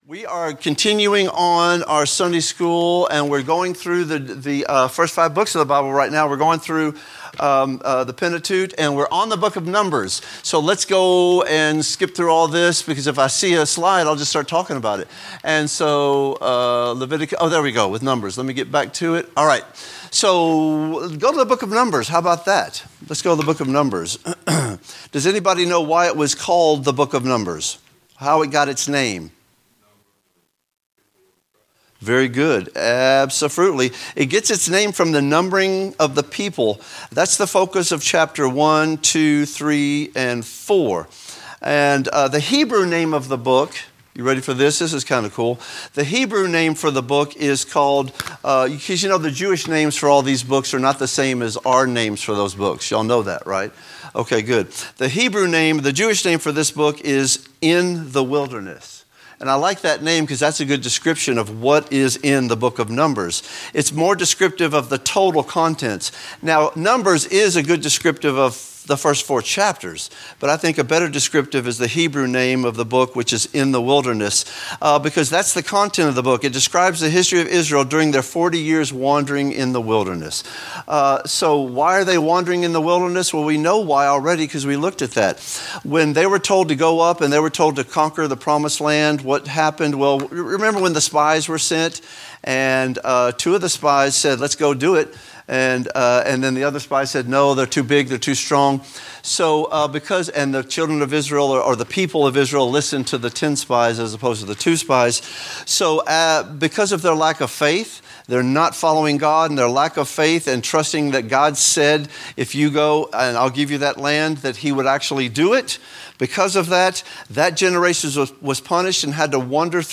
Lessons not part of a specific series